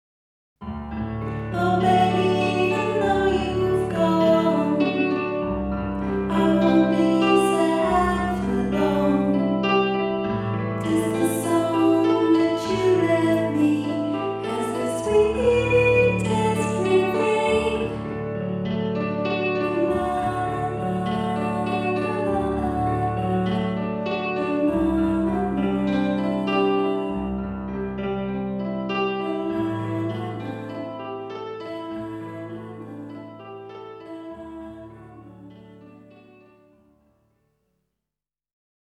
Жанр: Pop, Alternative, Indie Pop, Synthpop, Dance-Pop